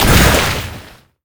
water_blast_projectile_spell_04.wav